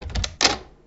Door_Open_1.ogg